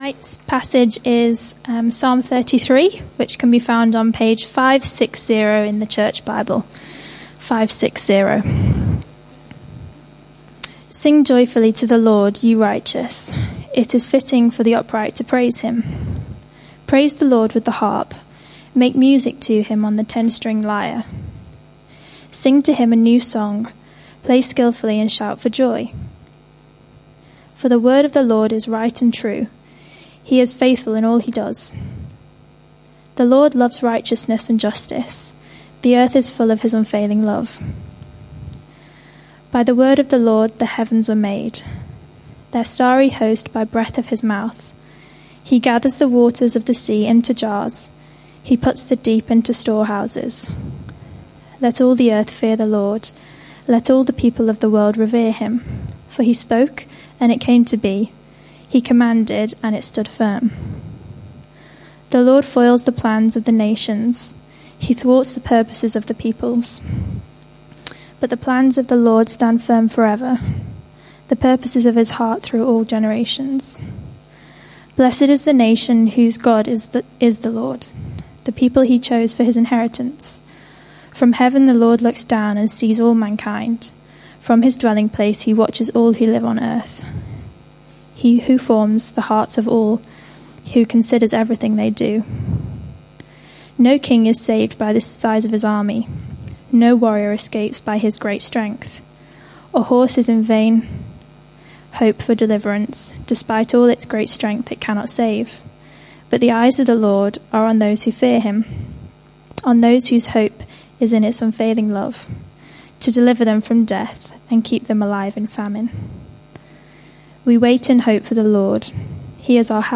This sermon is part of a series